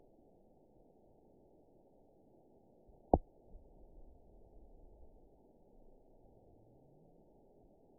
event 919871 date 01/26/24 time 16:49:57 GMT (1 year, 3 months ago) score 9.57 location TSS-AB04 detected by nrw target species NRW annotations +NRW Spectrogram: Frequency (kHz) vs. Time (s) audio not available .wav